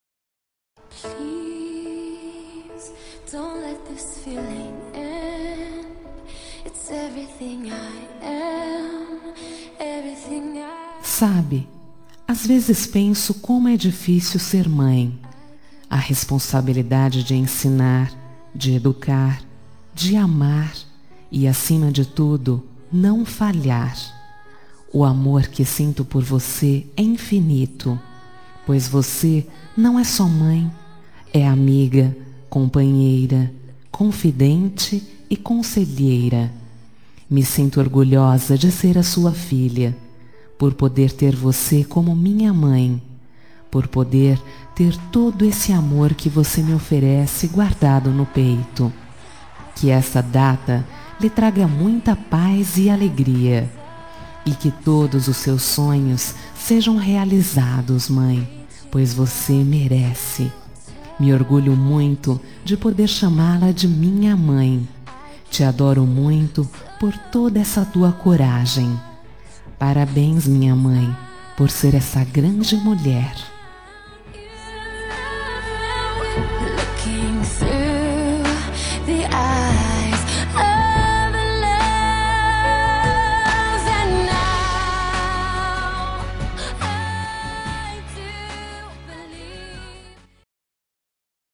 Telemensagem Aniversário de Mãe – Voz Feminina – Cód: 1397